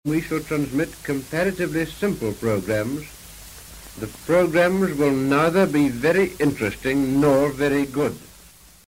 Witness the launch in 1932 of what was to become the World’s largest radio station: the BBC Empire Service.